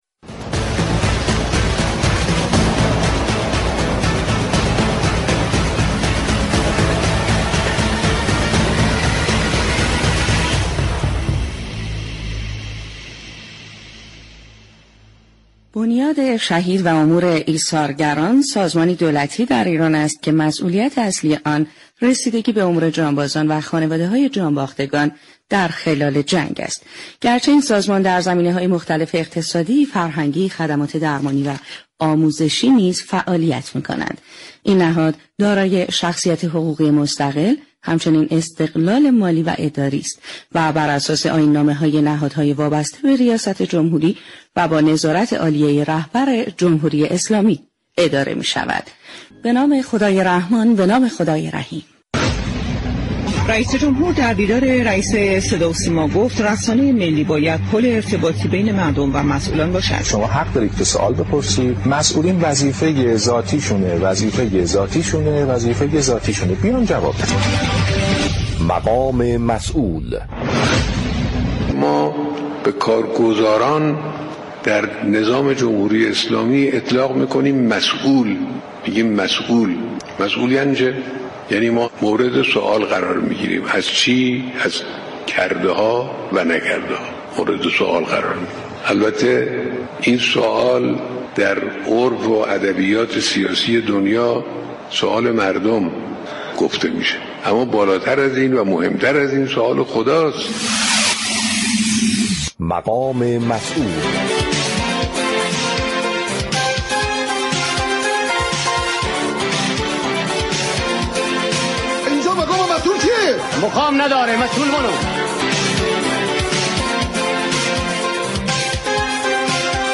محمود پاكدل معاون خدمات مددكاری و مشاركت‌های مردمی بنیاد شهید و امور ایثارگران كشور در گفت و گو با برنامه «پل مدیریت» رادیو تهران درباره ماهیت وجودی و وظایف معاونت متبوع خود گفت: ساختار تشكیلاتی بنیاد از ابتدای سال جاری تغییراتی داشته كه یكی از این تغییرات معاونتی ، معاونت مددكاری و مشاركت‌های مردمی است.